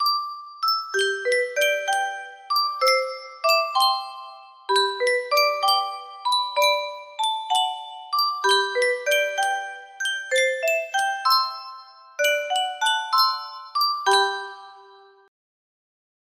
Yunsheng Music Box - The Cow Ate the Piper 6189 music box melody
Full range 60